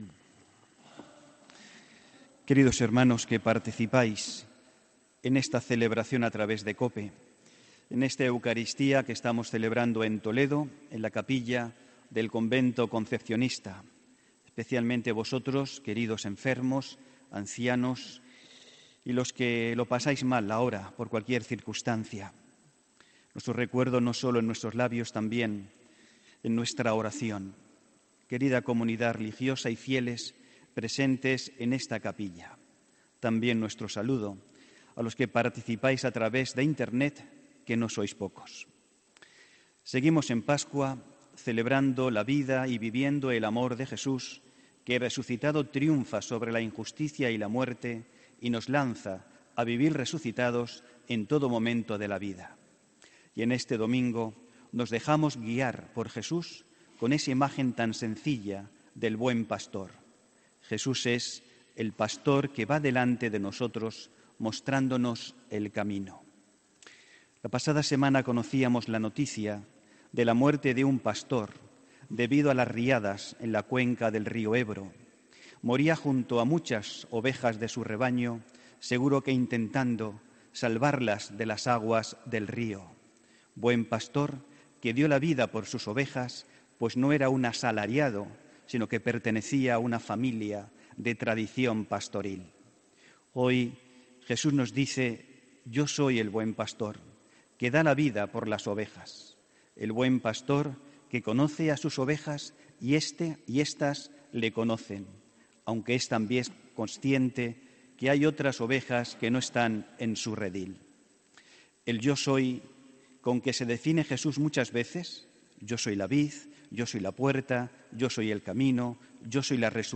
HOMILÍA 22 ABRIL 2018